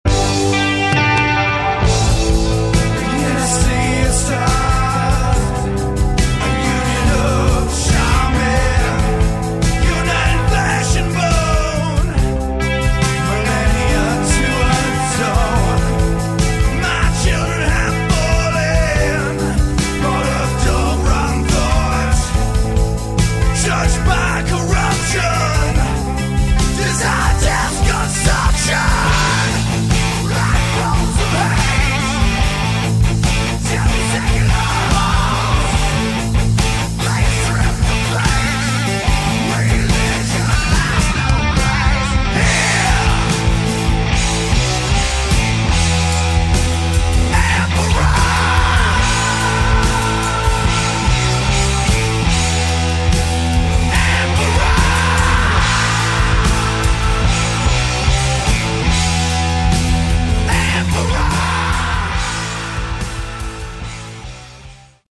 Category: Hard Rock
bass, lead vocals
guitars, vocals
percussion, vocals